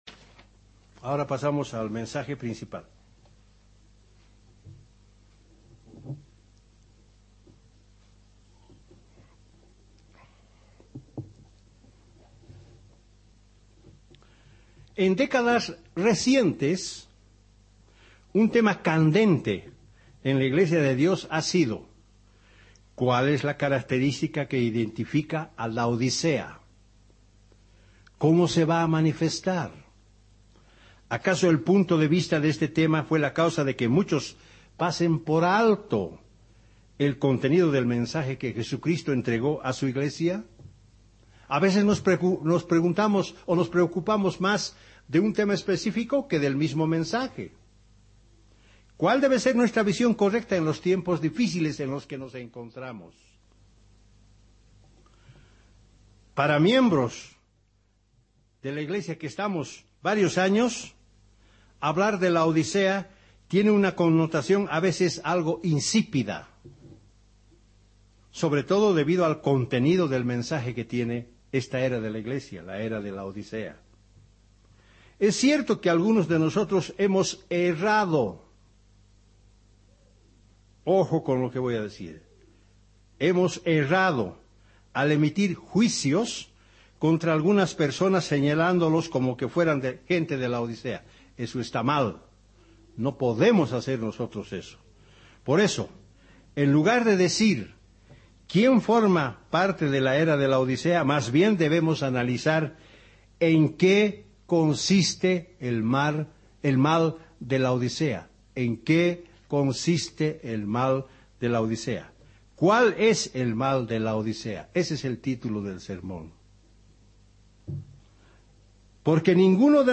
Muchos se preguntan ¿Cuál y quiénes estarán en la era de Laodicea? señalando una situación de la Iglesia de Dios en el tiempo del fin pasando por alto el mensaje que el Eterno quiere entregarnos. Descubra en este sermón en qué consiste el mal de Laodisea y prepárese para entender el mensaje de advertencia que encierra.